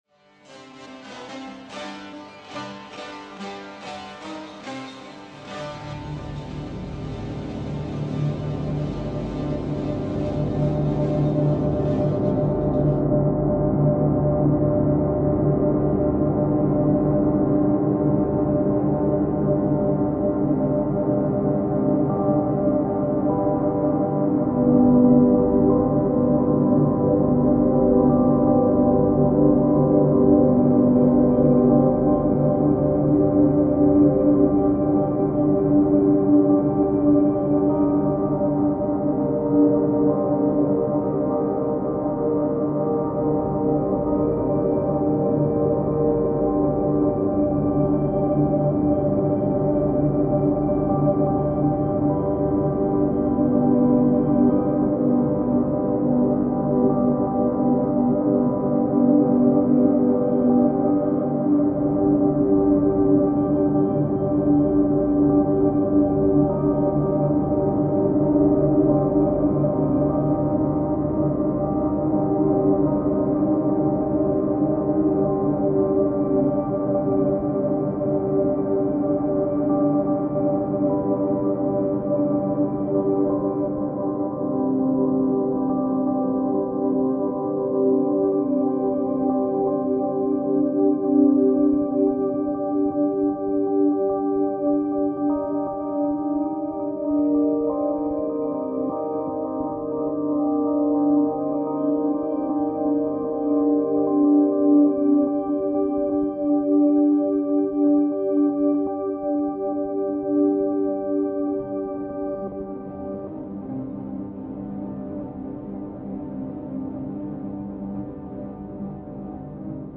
From there, I chopped out the main musical theme and then I dissected that section into even smaller bits.
I then played along with in on my piano and came up with the main musical parts which I decided to change from piano to synthesiser to help meld the sounds of the original audio with the new audio. I chose the title "Cezve" after a Turkish long-handled coffee pot since the sound was "brewed" into a deep liquid texture similar to Turkish coffee.
Turkish folk songs